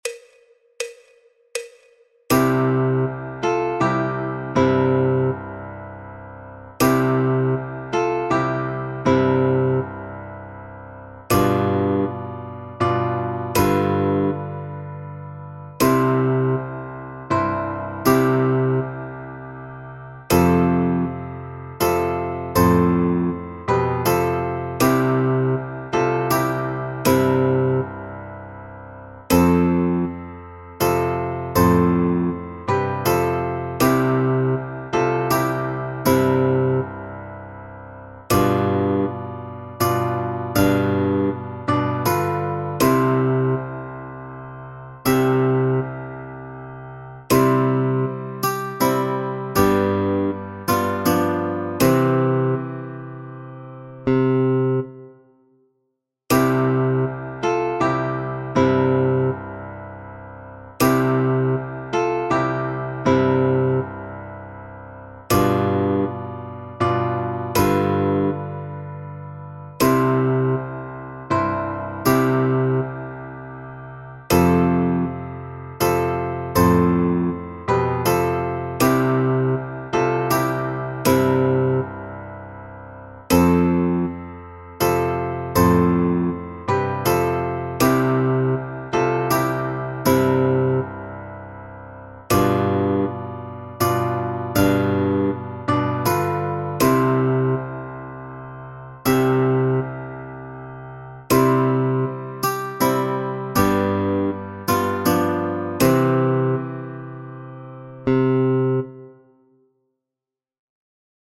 Punteo de Guitarra en Do Mayor
Bandurria
Villancicos